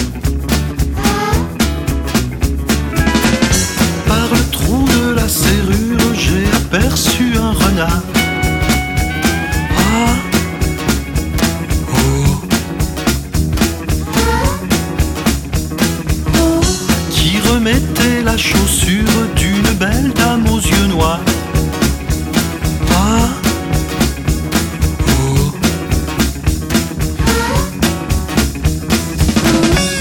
Chanson enfantine